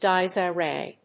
音節Di・es I・rae 発音記号・読み方
/díːeɪsíːreɪ(米国英語), díːeɪzíərɑɪ(英国英語)/